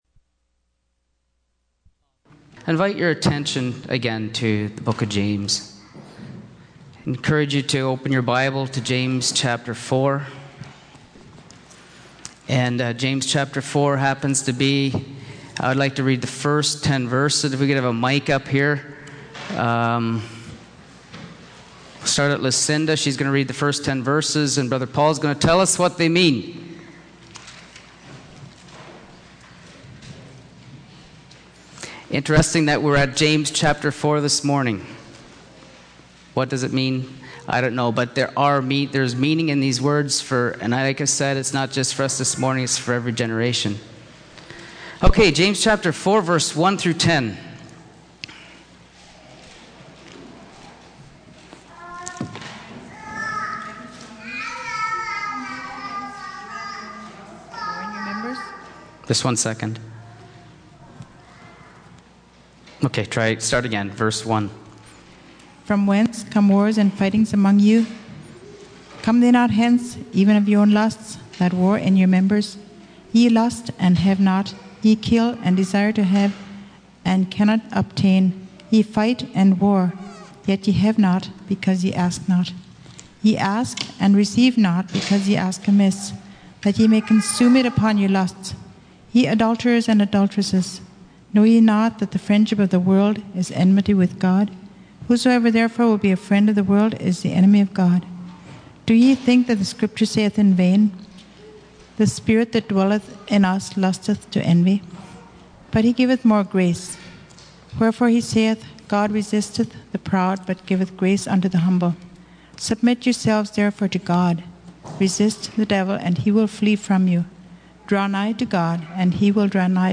James 4:1-17 Service Type: Sunday Morning %todo_render% « Unity in the Church God